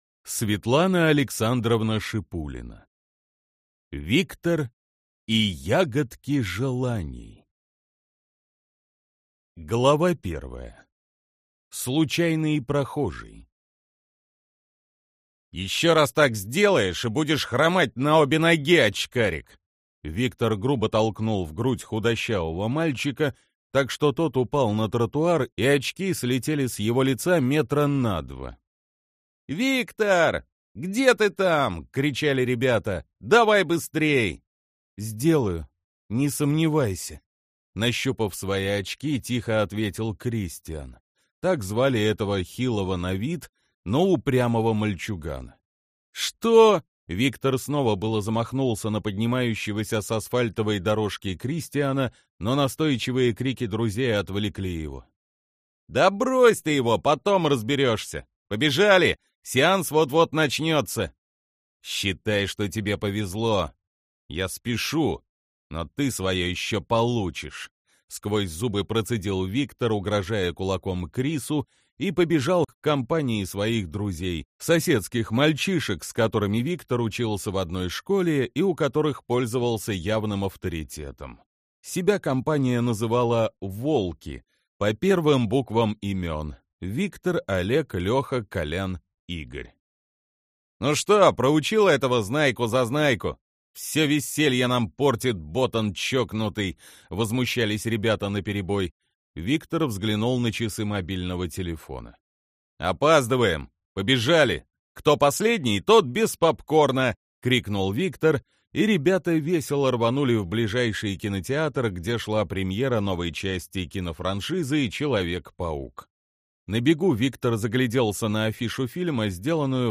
Аудиокнига Виктор и ягодки желаний, или Путешествие в Потаённую страну | Библиотека аудиокниг